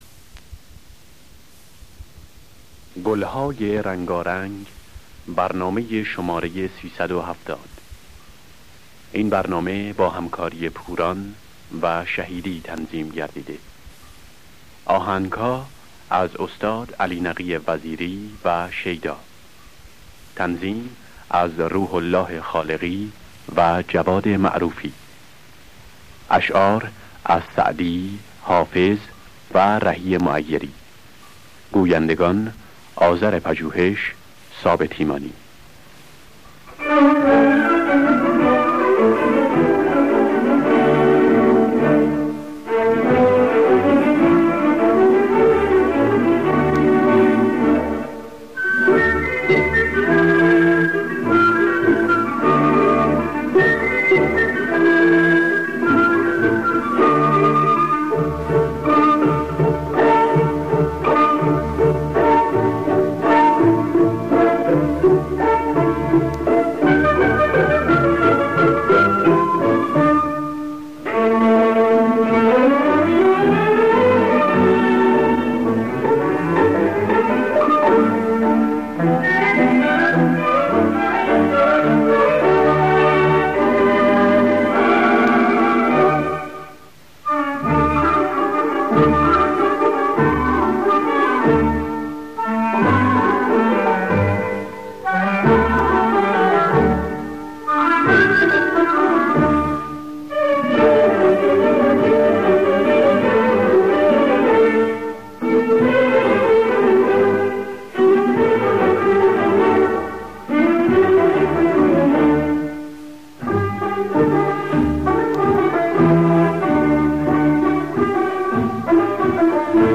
گلهای رنگارنگ ۳۷۰ - ماهور